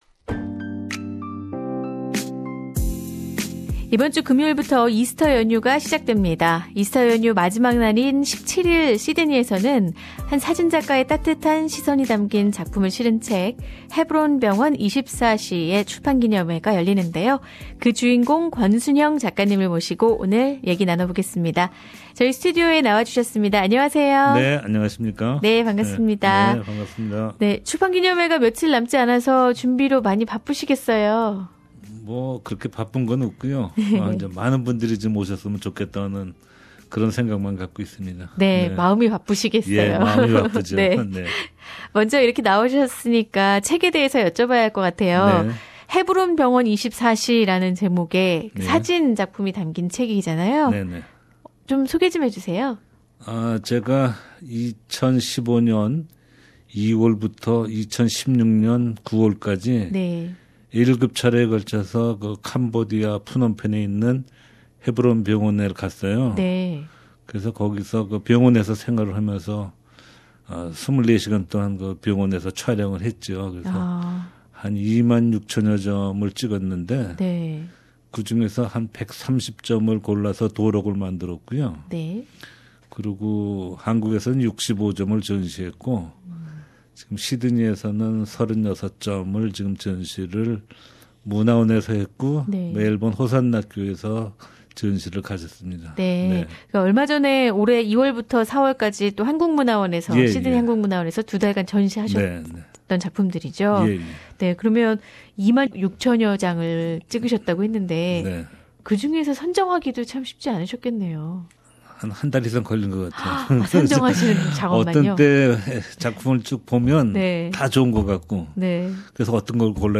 대담